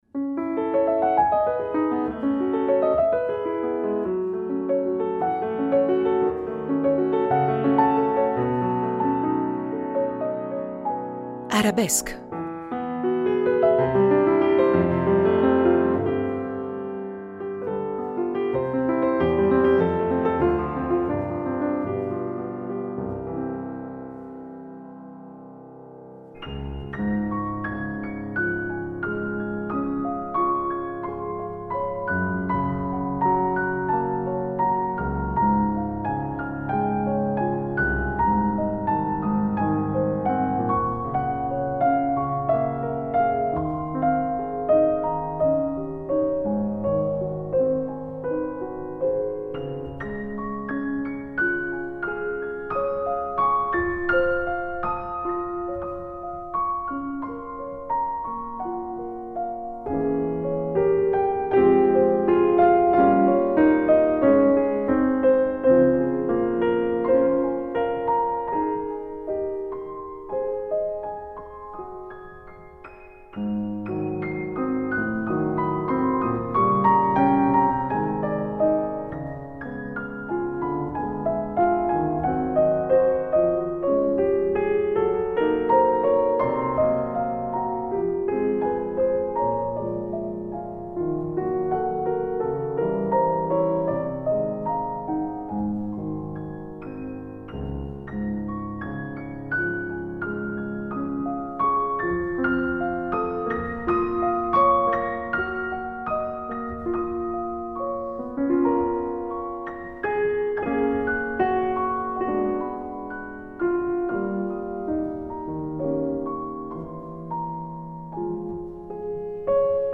La prima parte dell’incontro si apre con un breve estratto dagli Studi Sinfonici di Schumann.